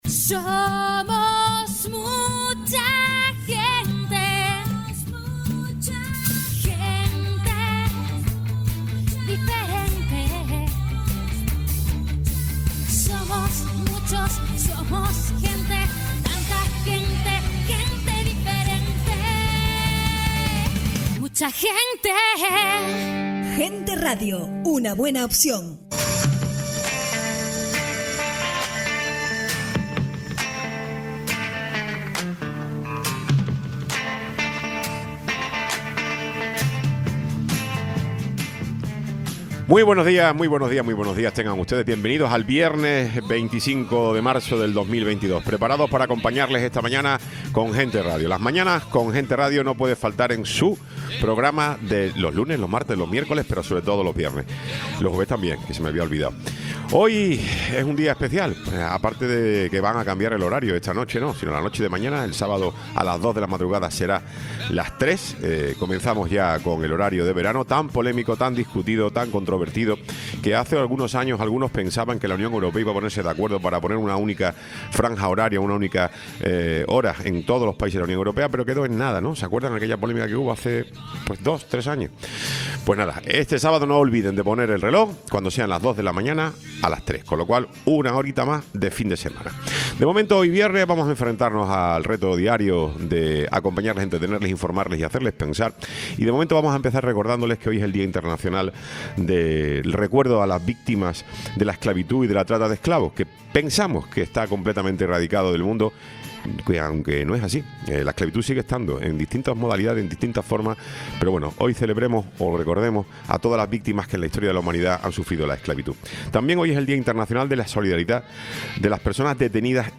Tertulia de mujeres políticas